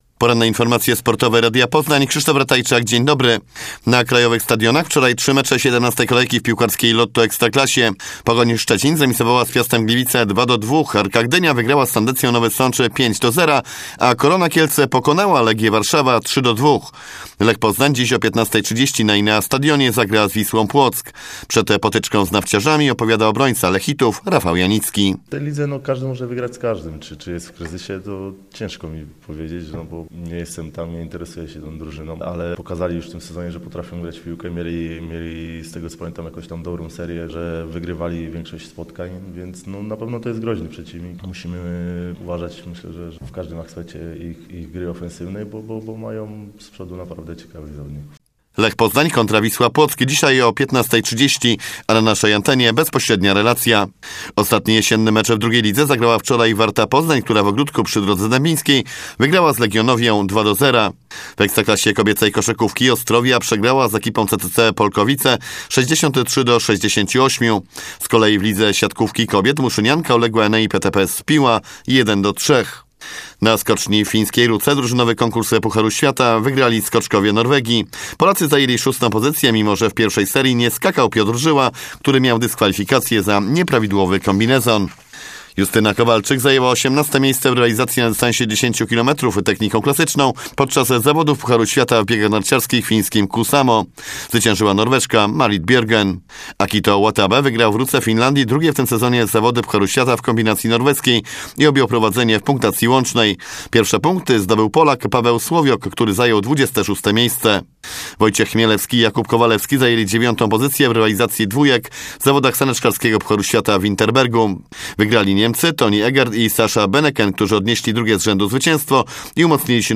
26.11 serwis sportowy godz. 9:05